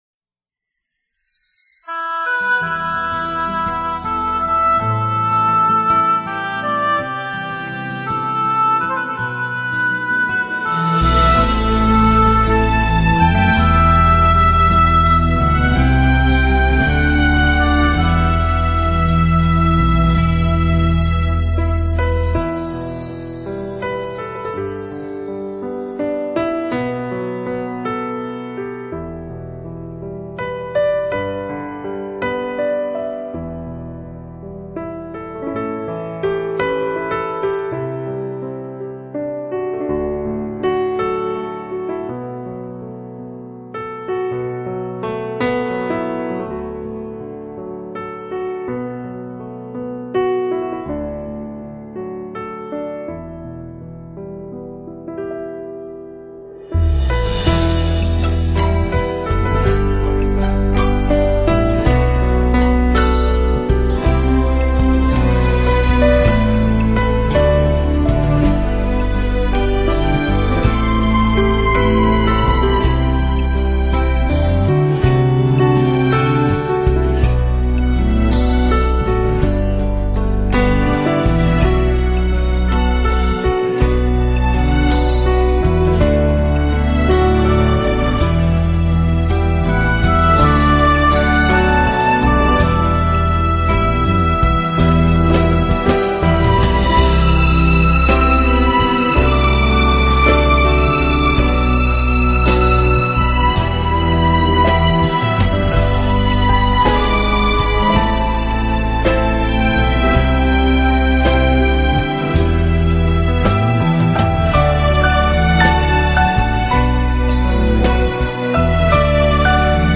Trình Bày : Hòa Tấu